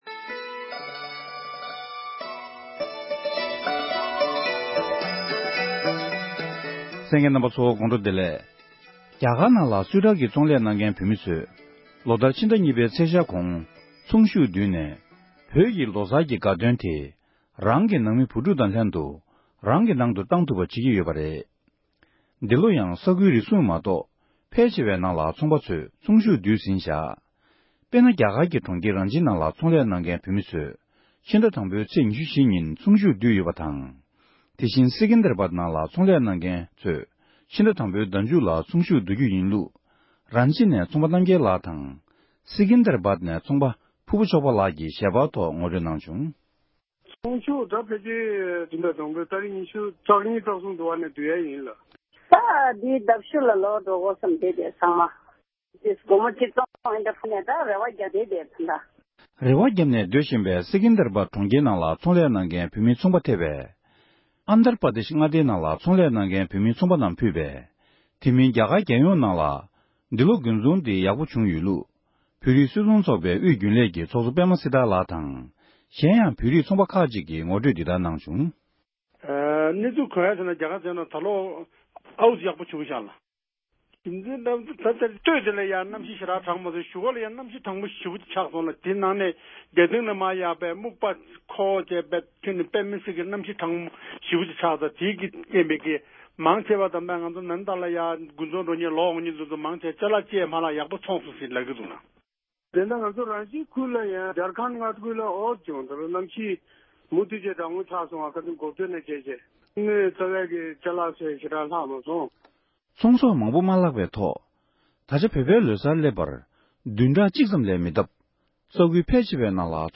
གནས་འདྲི་ཞུས་ཏེ་ཕྱོགས་བསྒྲིགས་ཞུས་པ་ཞིག་གསན་རོགས༎